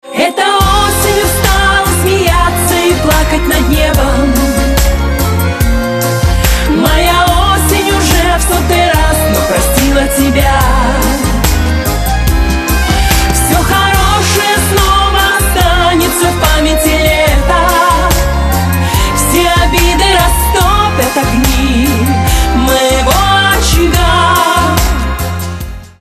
женский вокал
русский шансон